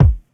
Boom-Bap Kick 95.wav